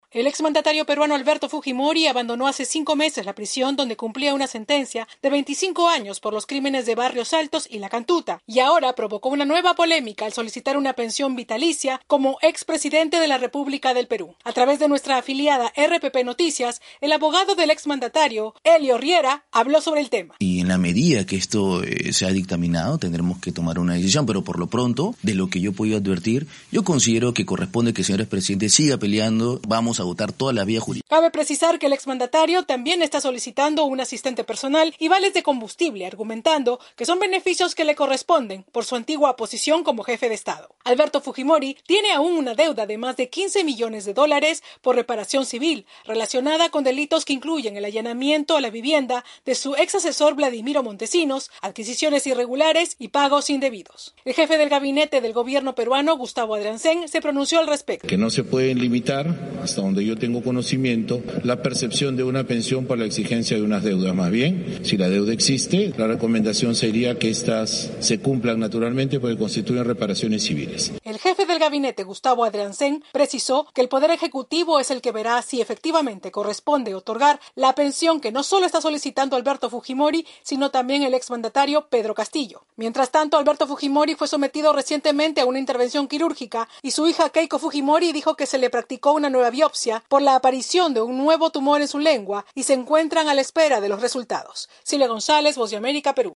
El expresidente del Perú, Alberto Fujimori, provocó diversas reacciones por su solicitud de una pensión vitalicia pese a que fue condenado por violación de derechos humanos durante su mandato. El informe